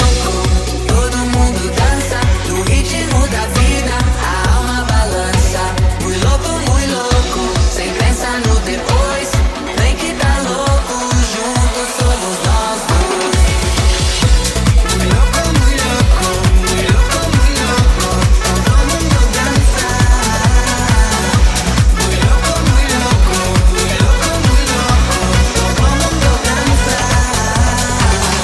Genere: pop,dance,afrobeat,house,latin,edm,remixhit